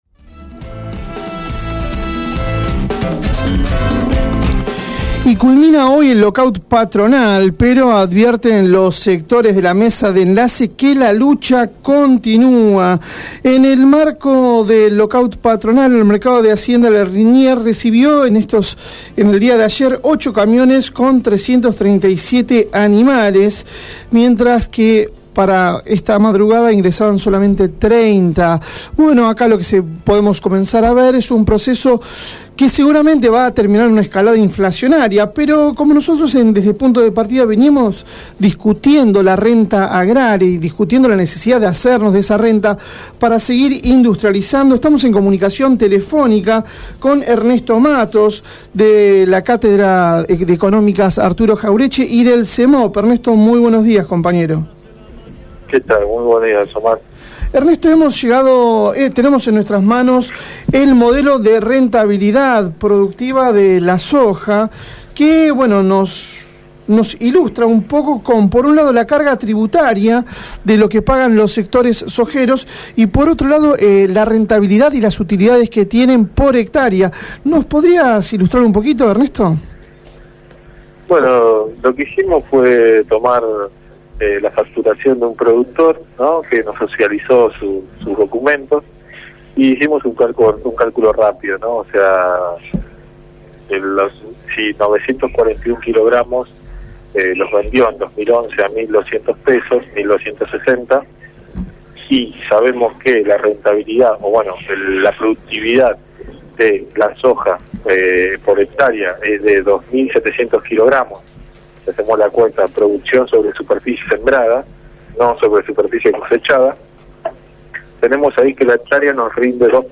En comunicación telefónica